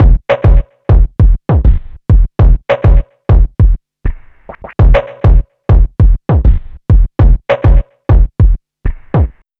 noise beat 100bpm 01.wav